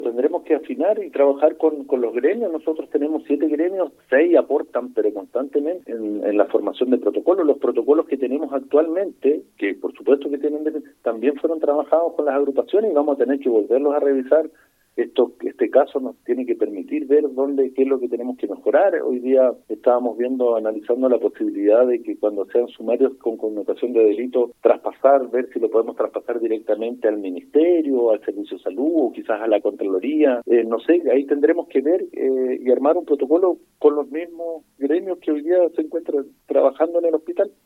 En entrevista con Radio Bío Bío en la ciudad, la autoridad sanitaria fue consultada por el ánimo que hay entre los trabajadores tras lo expuesto, replicando que hay “una consternación de toda la comunidad hospitalaria, esto nos ha pegado muy fuerte“.